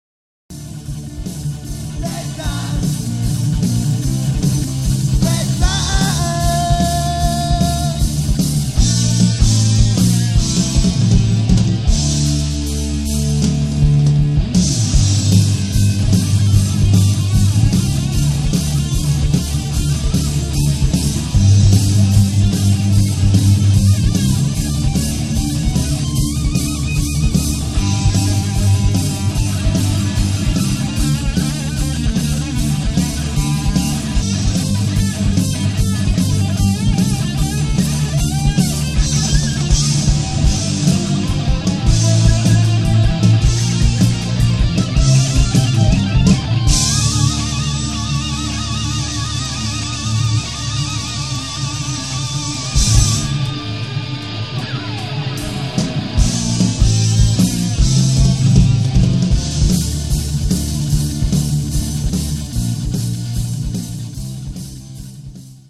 パワー溢れるボーカル、３重によるギターの録音でより厚みを出している。